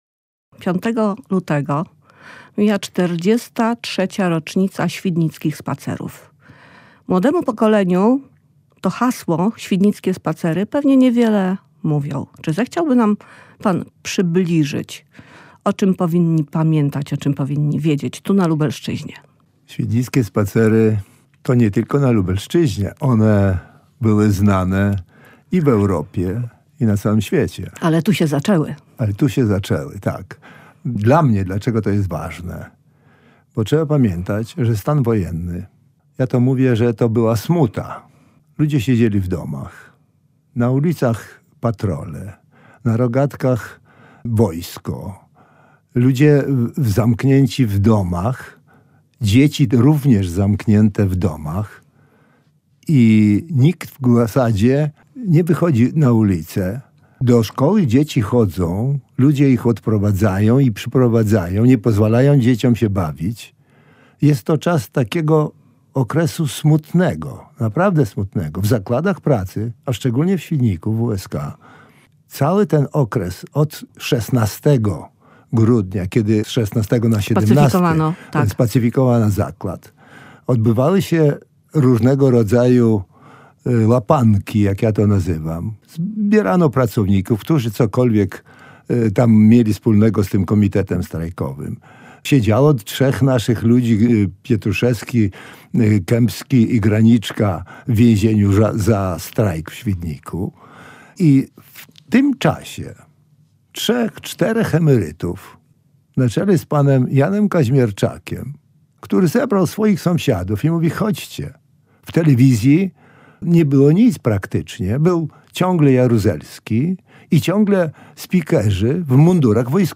Cała rozmowa w materiale audio: